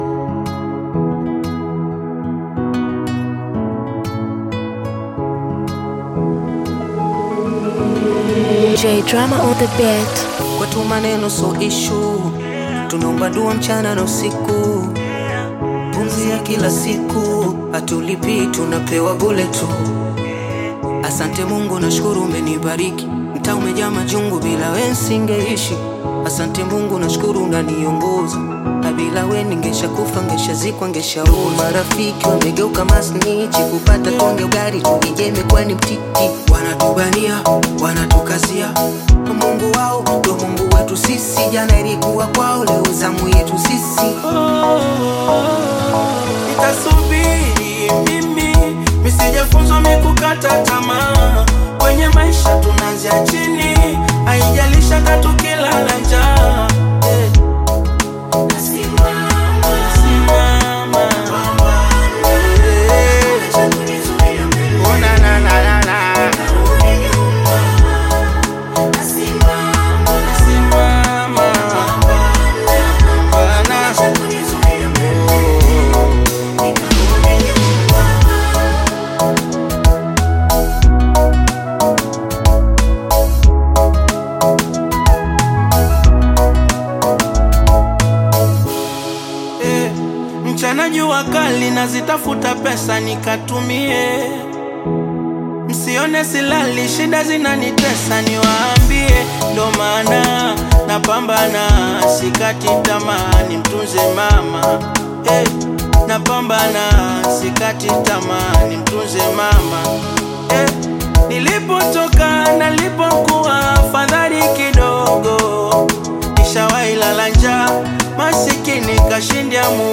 strong vocals